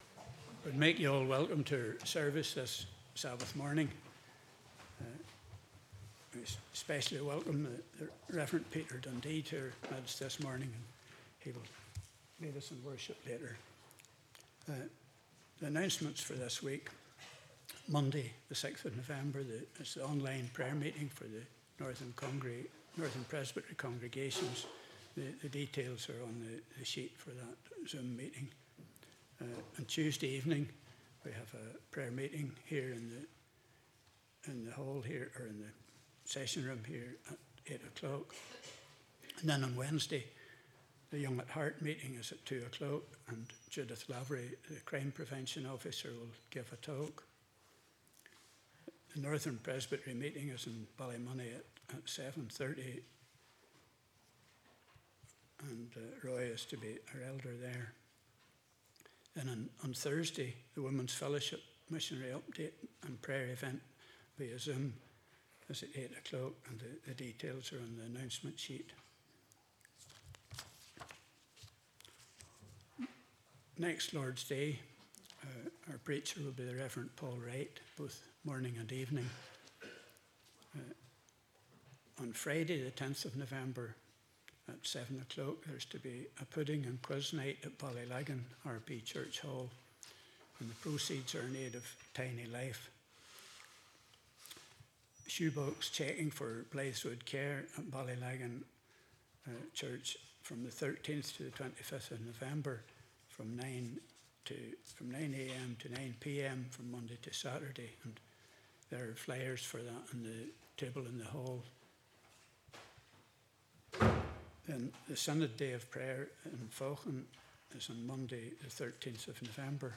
Due to technical difficulties the end of the sermon was not recorded but the last psalm and prayer was.
Passage: Psalm 119:153-159 Service Type: Morning Service